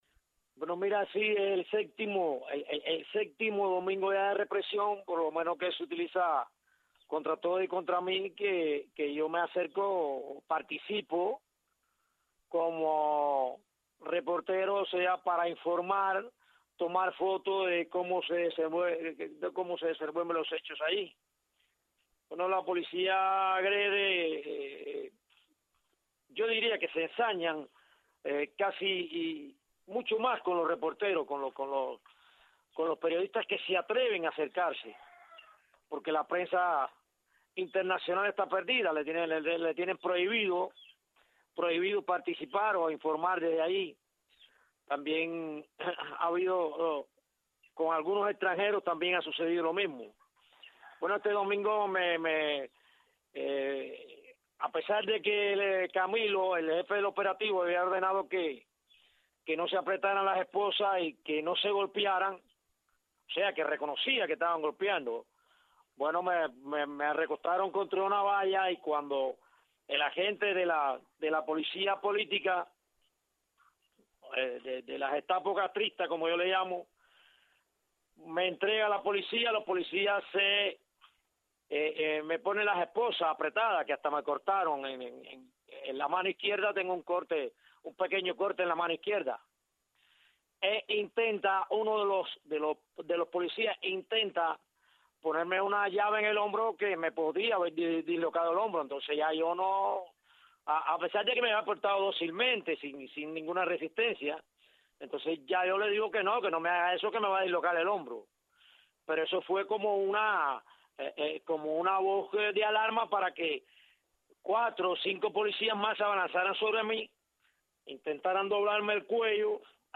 Tres reporteros relatan lo vivido el domingo en La Habana y denuncian los métodos crueles y degradantes que usan las fuerzas militares contra la sociedad civil.